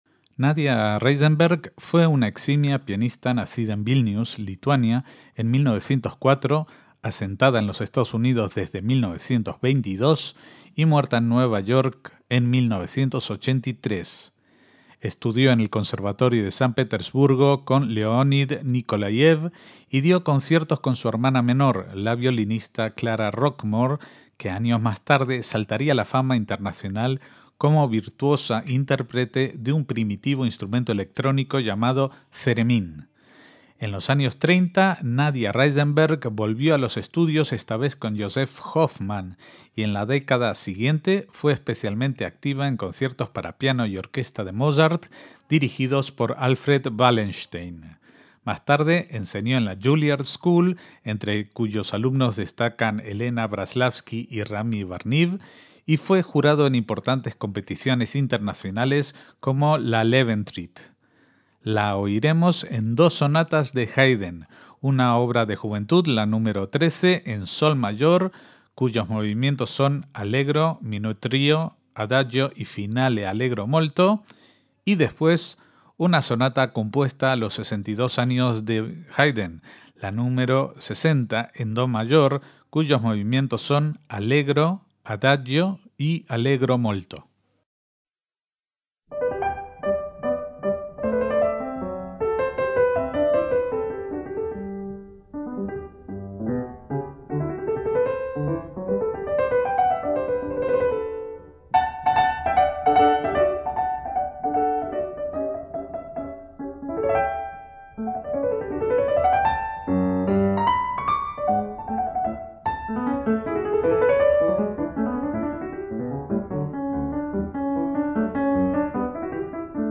MÚSICA CLÁSICA - Nadia Reisenberg fue una destacada pianista nacida en Vilnius, hoy Lituania, en 1904 y fallecida en Nueva York en 1983.